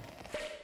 HeadInflatePOP.wav